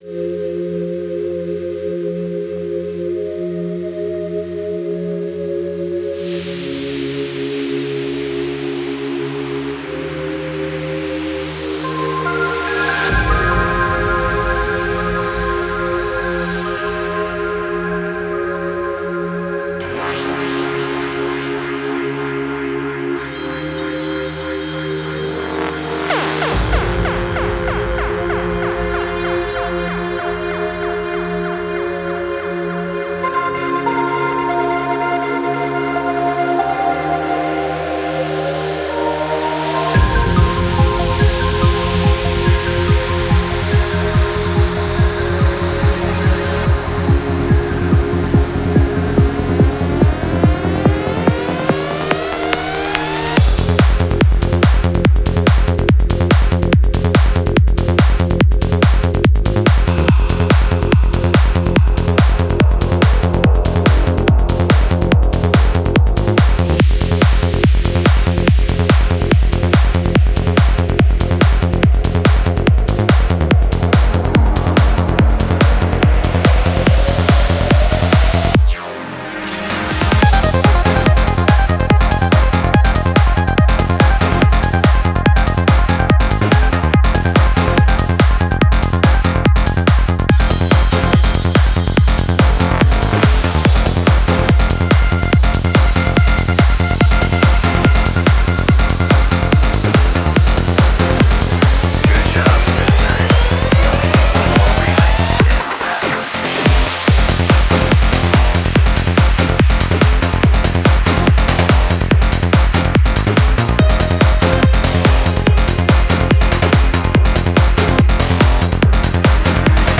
Style: FullOn, Pop